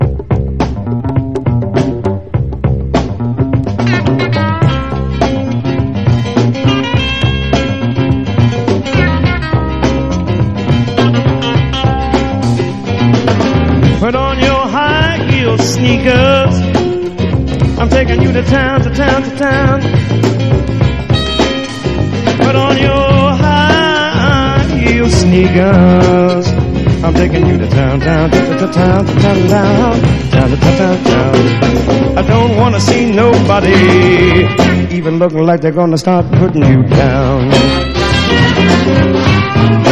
EASY LISTENING / OTHER / OLDIES / NORTHERN SOUL / EARLY SOUL
ガール・ポップ～ドゥーワップ～ノーザン・ソウルの魅力が詰まった最高青春チューン！